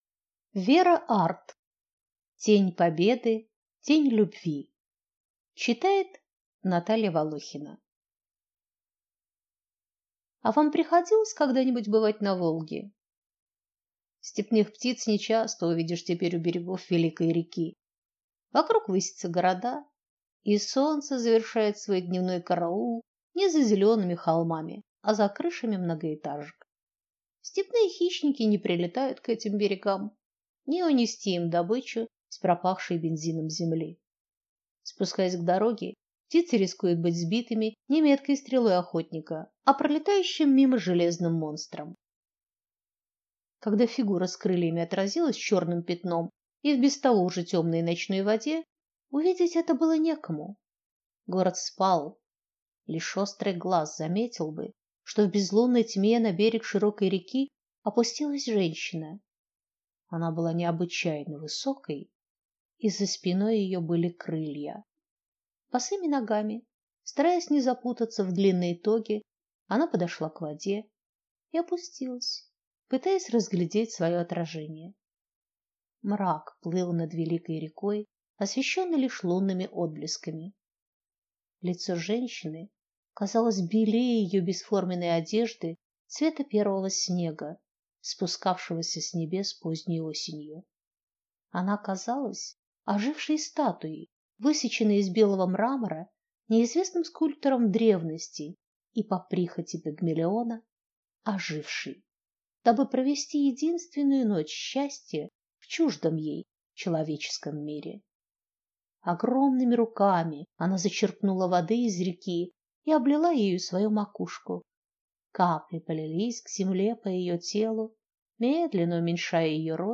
Аудиокнига Тень Победы, Тень Любви | Библиотека аудиокниг
Прослушать и бесплатно скачать фрагмент аудиокниги